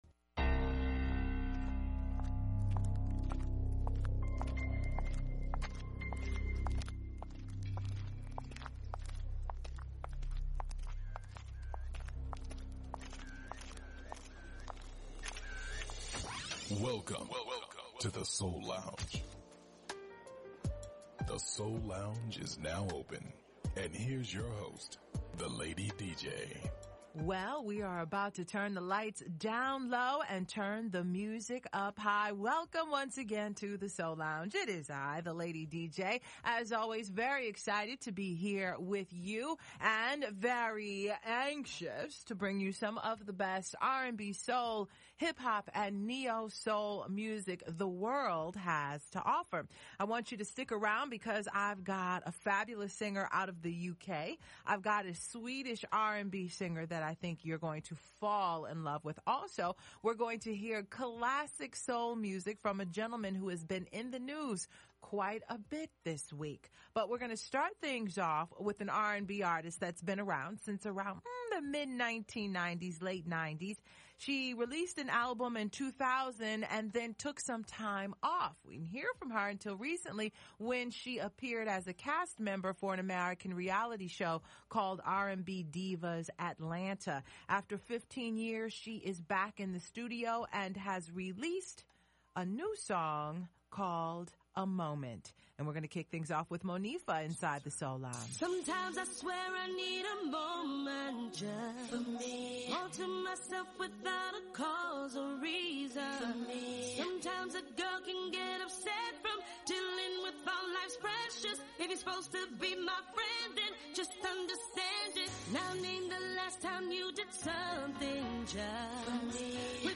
music, interviews and performances
Neo-Soul
conscious Hip-Hop
Classic Soul